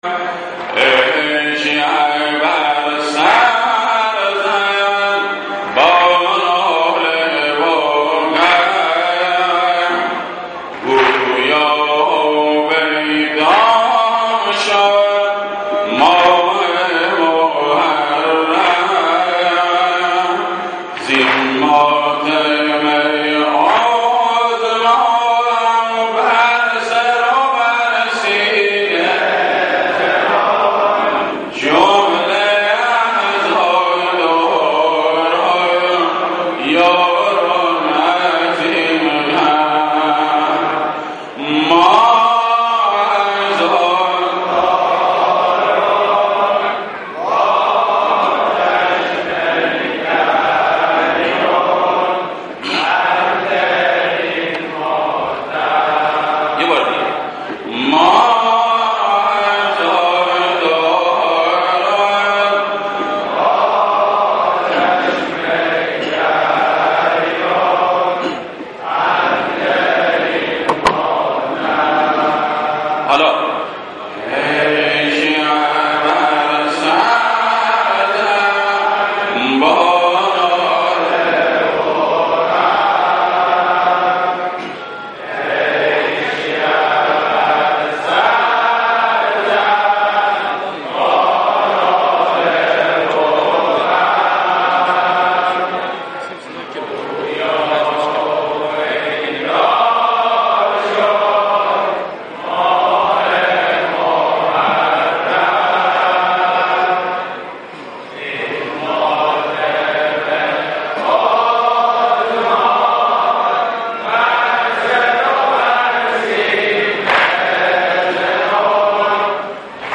عزاداری سنتی بوشهر.mp3
عزاداری-سنتی-بوشهر.mp3